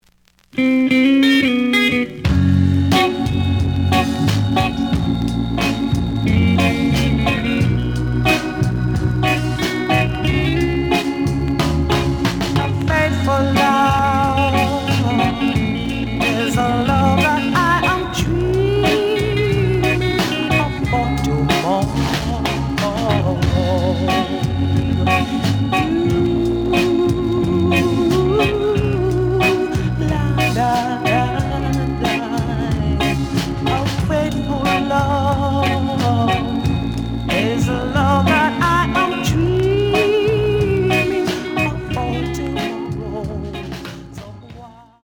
The audio sample is recorded from the actual item.
●Genre: Rock Steady